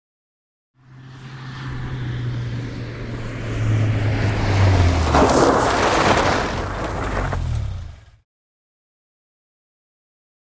Звук автомобиль Лада (21014) подъезжает и тормозит на гравии (00:11)